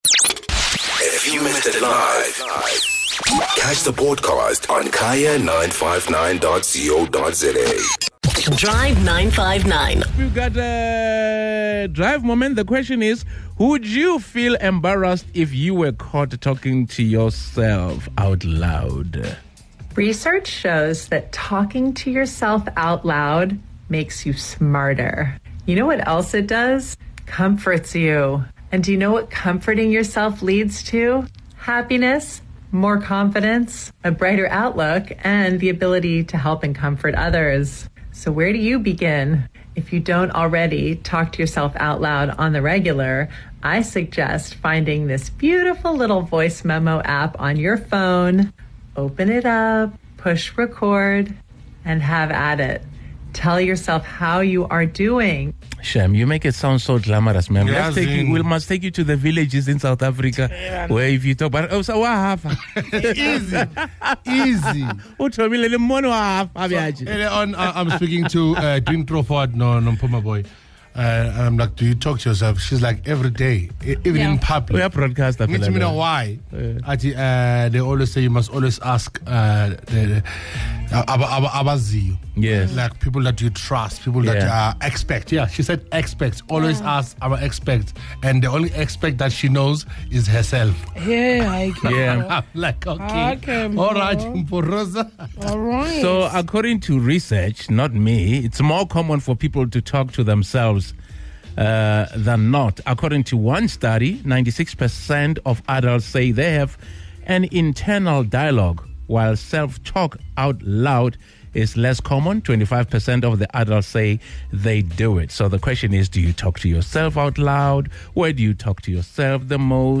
The Drive Team asks listeners... let's hear what they had to say!